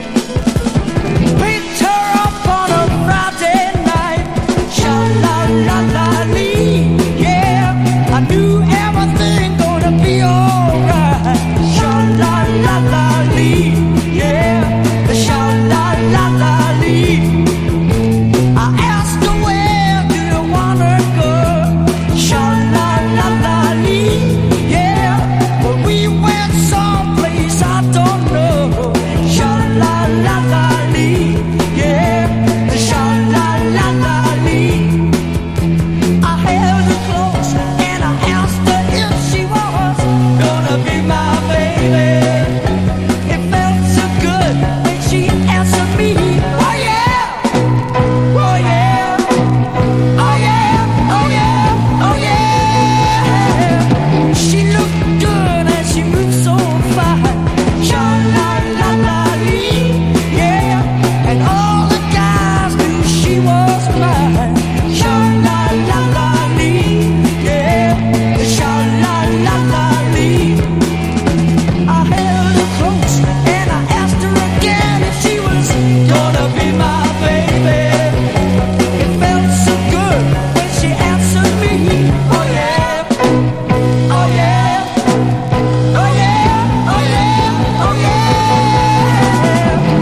1. 60'S ROCK >
R&B〜ブルーアイドソウルをガレージに昇華したカッコよさ！